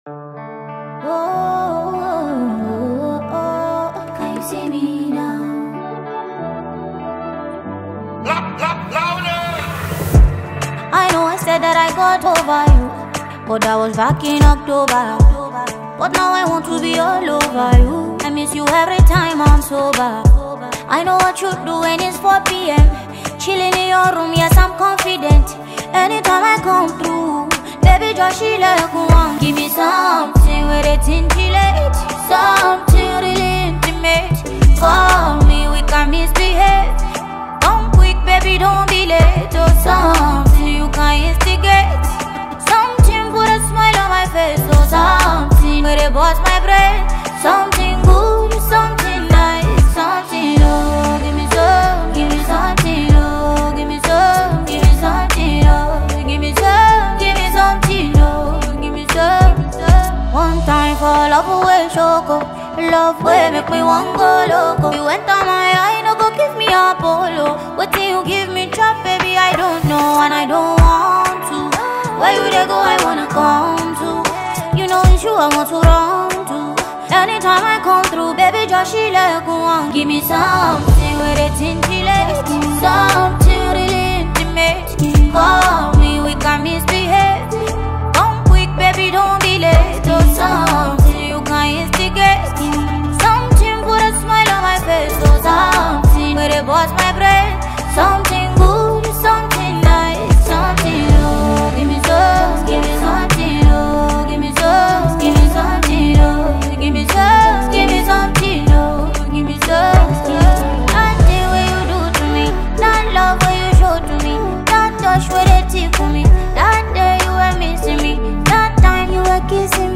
Vocally gifted Nigerian singer-songwriter and performer
Genre: Afrobeats